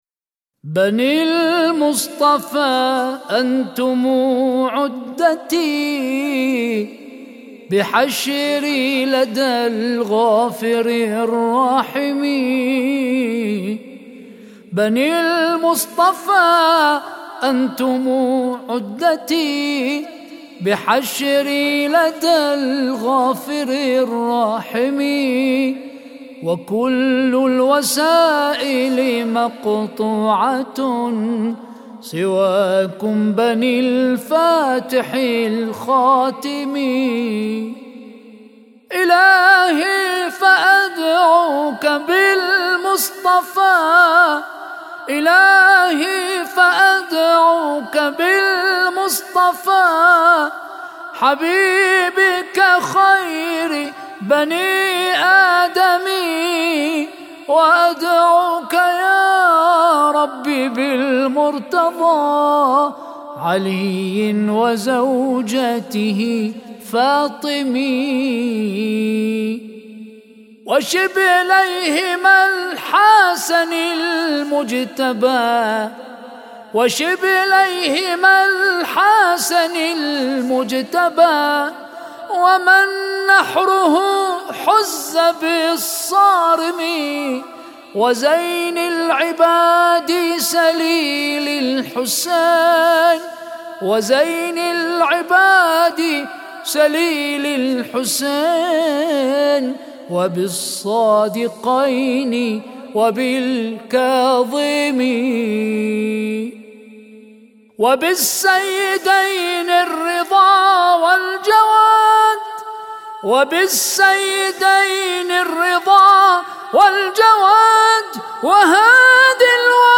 مدائح